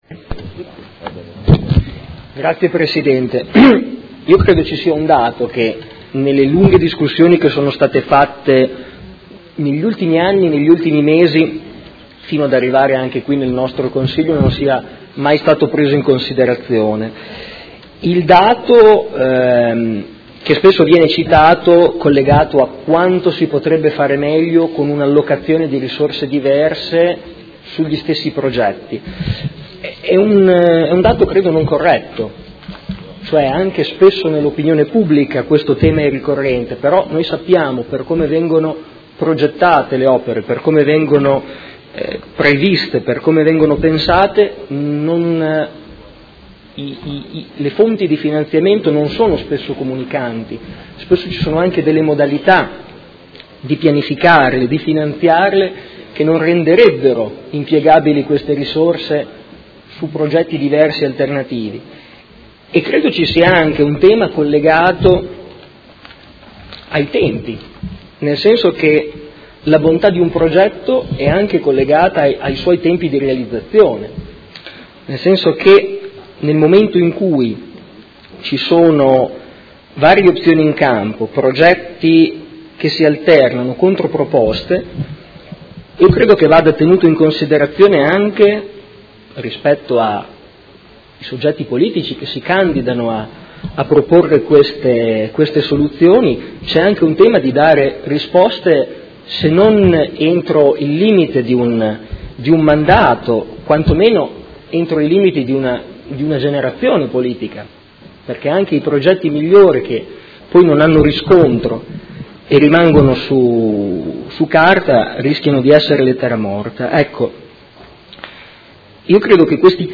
Seduta del 28/03/2019. Dibattito su Ordine del Giorno presentato dai Consiglieri Stella (SUM), dal Consigliere Chincarini (Modena Volta Pagina) e dal Consigliere Bussetti (M5S) avente per oggetto: Bretella autostradale Sassuolo- Campogalliano: opera inutile e dannosa per il nostro ambiente: finanziare la manutenzione della viabilità esistente e sviluppare il potenziamento e la qualificazione delle tratte ferroviarie Sassuolo- Modena e Sassuolo-Reggio, ed emendamento; Ordine del Giorno Prot. Gen. n. 93778; Ordine del Giorno Prot. Ge. n. 93779